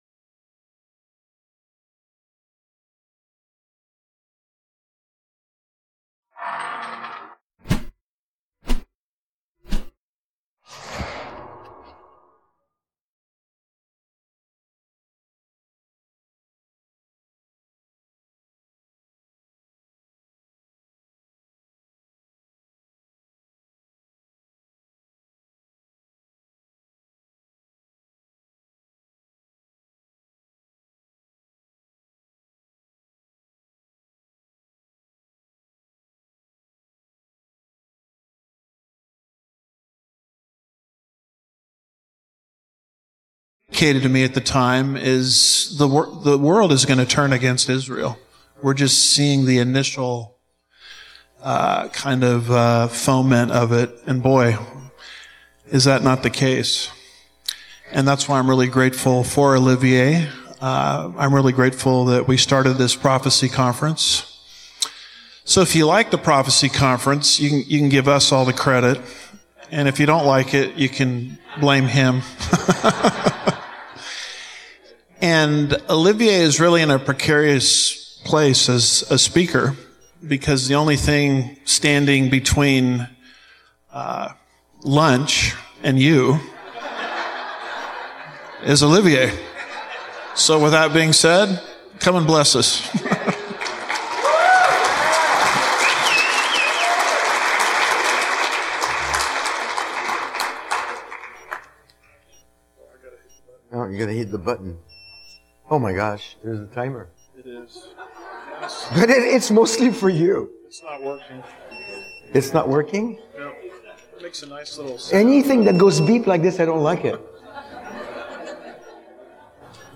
2026 Prophecy Conference